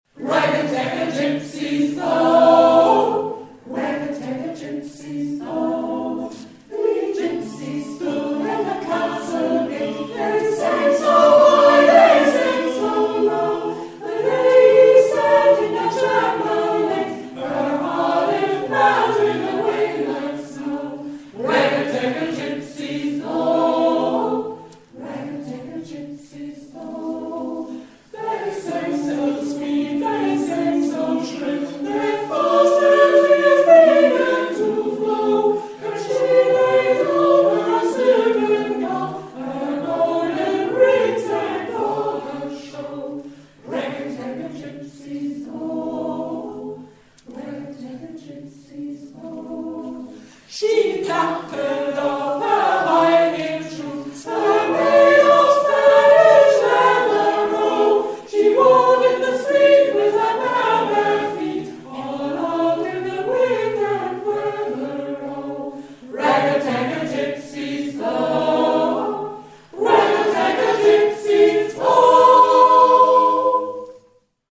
Ein paar Beispiele dafür, dass man auch mit einem kleinen Chor auf den Groove kommen kann.